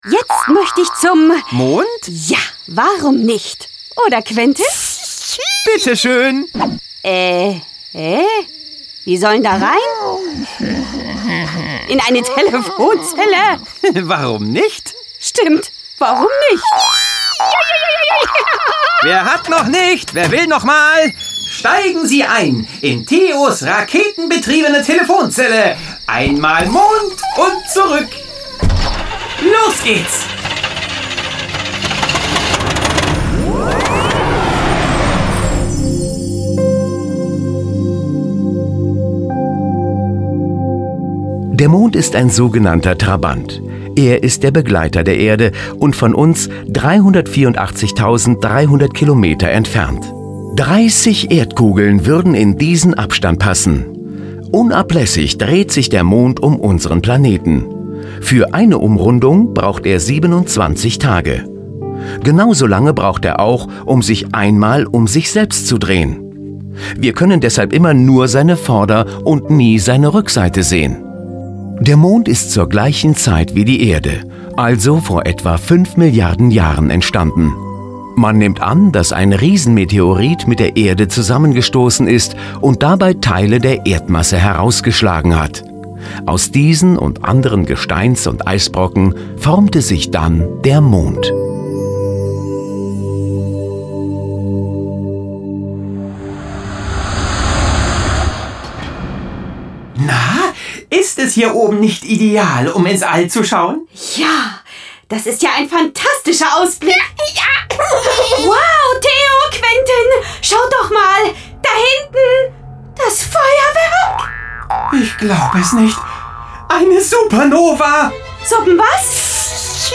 Schlagworte Ägypten • Ägypten (Alt-Ä.); Kindersachbuch/Jugendsachbuch • Altes Ägypten Pyramiden Nil Hochkultur Tempel Hieroglyphen Pharaonen Pyramiden Mumien • Echsen • Echsen; Kindersachbuch/Jugendsachbuch • Hörbuch; Hörspiel für Kinder/Jugendliche • Hörspiel-CD • Hörspiele Audio CD Was ist was für Kinder ab 6 Jahren auf Grundlage der Kinderbücher Sachbücher • Pyramiden • Schlangen • Schlangen Echsen Vögel Echsen Gecko Komodowaran Dinosaurier • Schlangen; Kindersachbuch/Jugendsachbuch • Stern (Astronomie); Kindersachbuch/Jugendsachbuch • Sterne • Sterne Zeit Raumfahrt Teleskop Milchstraße weißer Zwerg • Vögel • Vögel; Kindersachbuch/Jugendsachbuch • Vogel / Vögel; Kindersachbuch/Jugendsachbuch • Was ist Was • Zeit • Zeit / Zeitmessung; Kindersachbuch/Jugendsachbuch